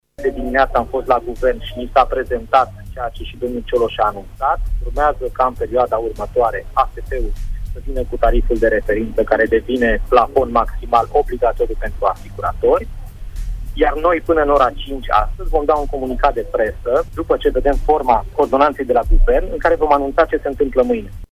a declarat, în urmă cu puțin timp, în emisiunea Pulsul zilei de la RTM, că așteaptă textul ordonanței de urgență pentru a lua o decizie finală: